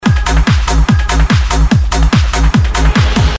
Does anyone know if its possible to create this kind of Donkeybass-sound on a Virus B?
Short clip of a song which use Donkeybass